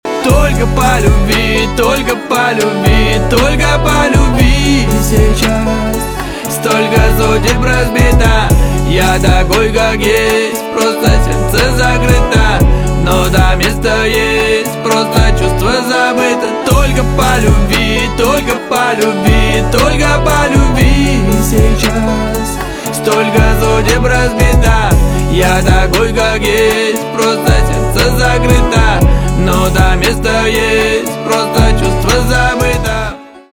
русский рэп
грустные , пацанские , битовые , басы , кайфовые